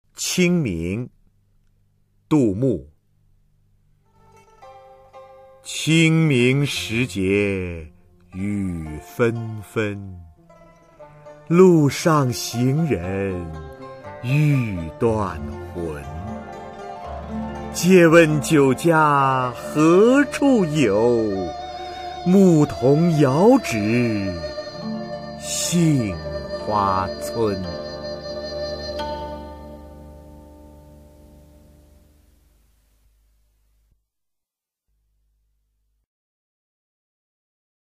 [隋唐诗词诵读]杜牧-清明 古诗文诵读